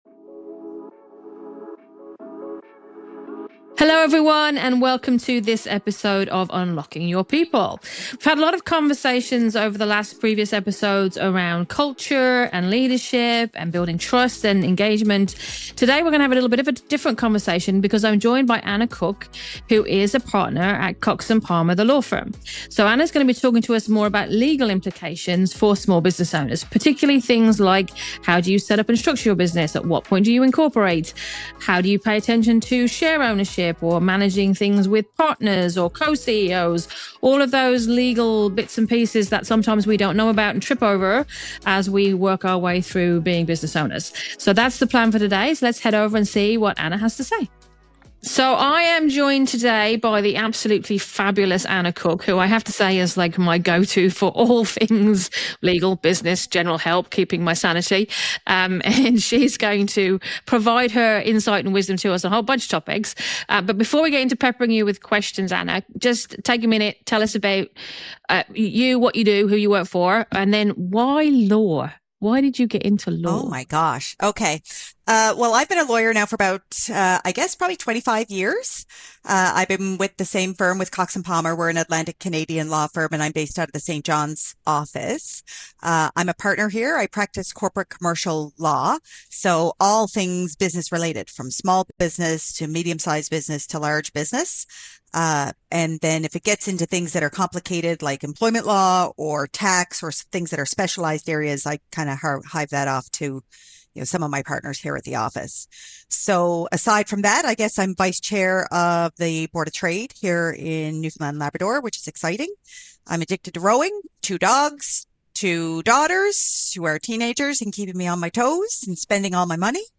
26 | Good Fences, Better Businesses: A Conversation on Legal Foundations